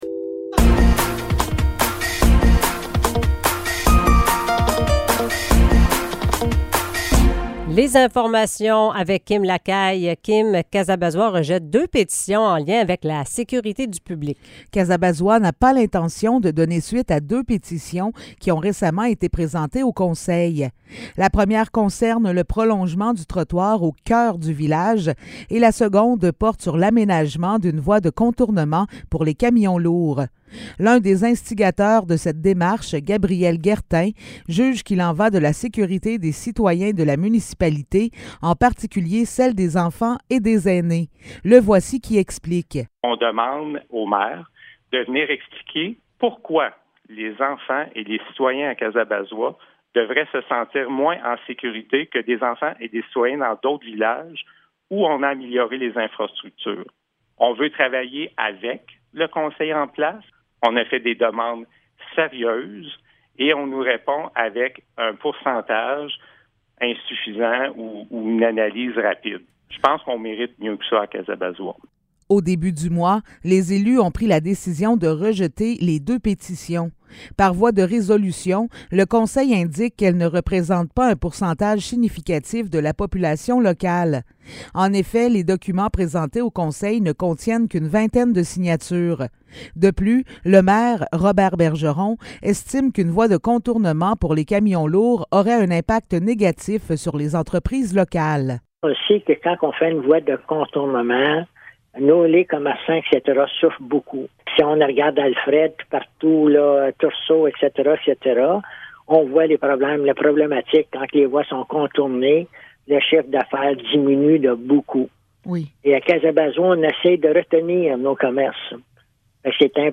Nouvelles locales - 15 mai 2023 - 8 h